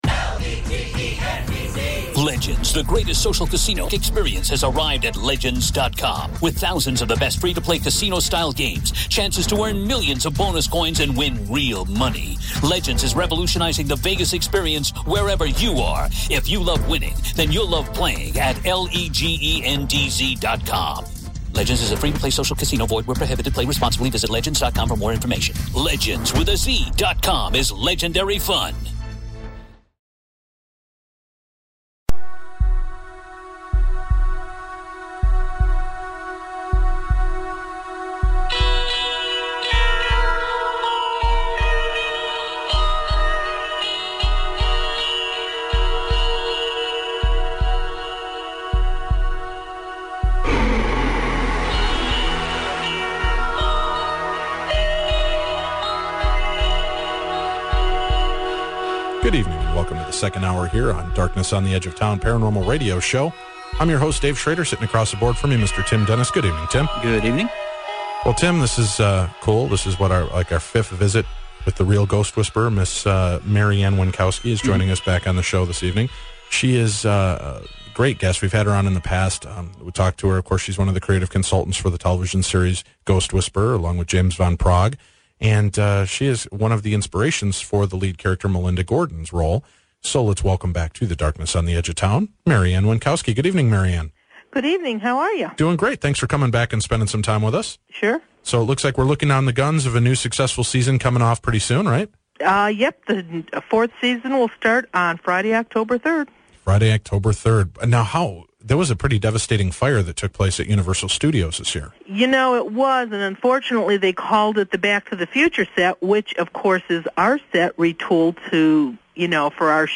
spends hour two with Darkness Radio taking calls from our listeners and telling them WHO the ghosts are in their house!!